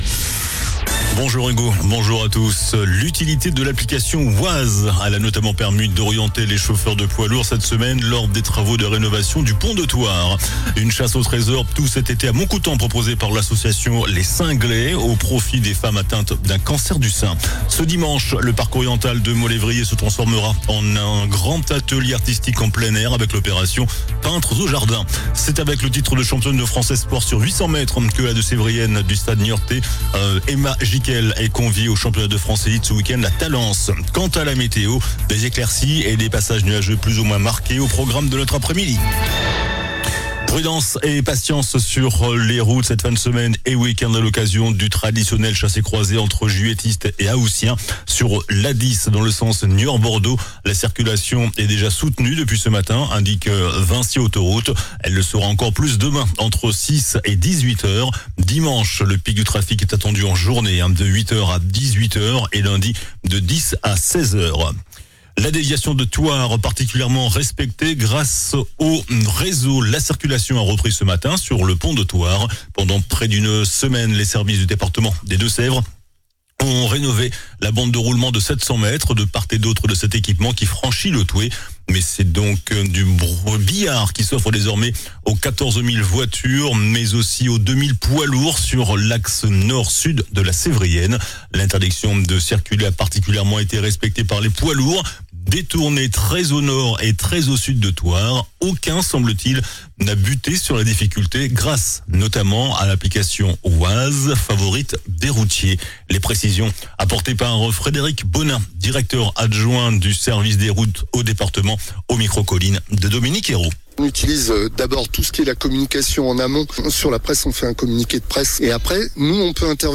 JOURNAL DU VENDREDI 01 AOÛt ( MIDI )